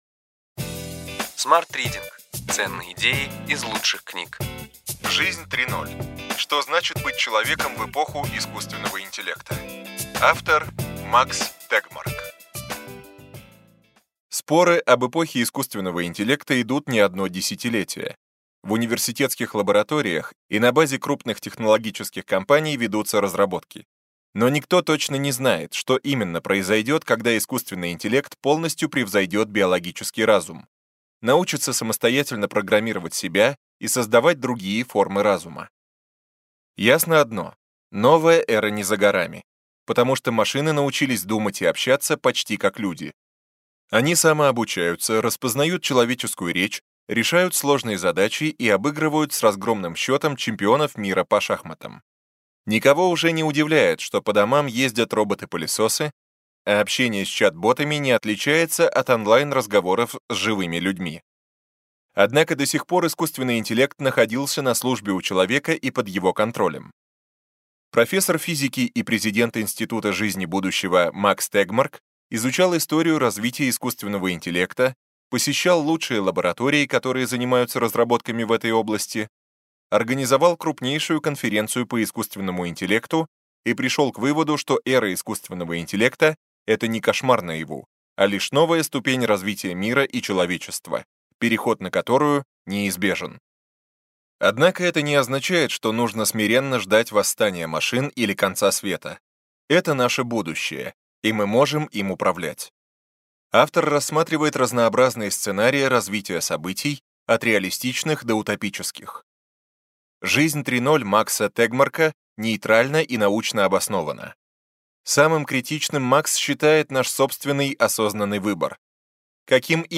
Аудиокнига Ключевые идеи книги: Жизнь 3.0: что значит быть человеком в эпоху искусственного интеллекта.